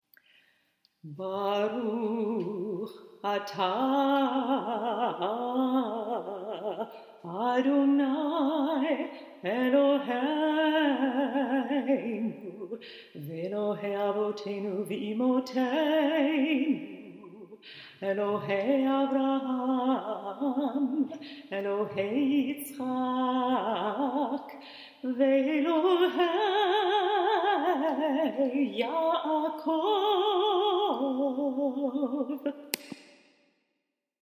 Some melodies are rhythmic and easy to sing, like "Bar'chu," while others, presumably older, are more irregular and chant-like - for example the chant for "
These melodies evoke something different than does our regular Shabbat worship.